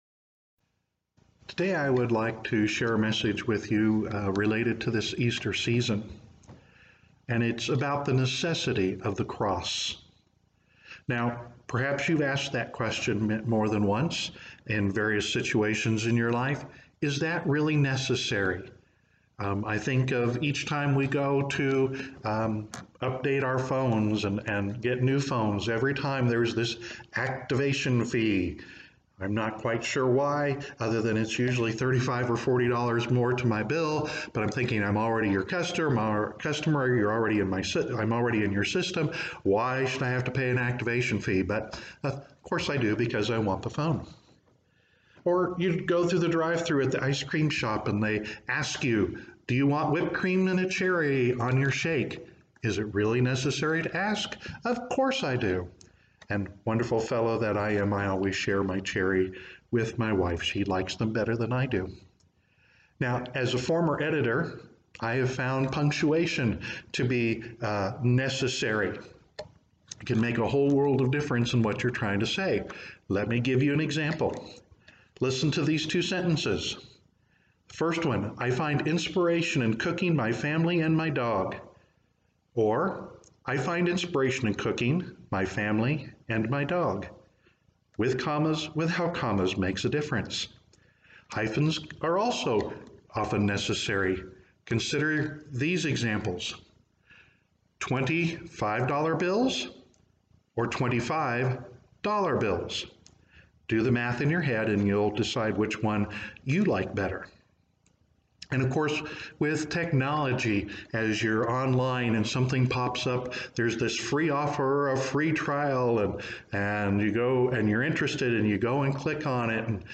Sermons | Friendship Assembly of God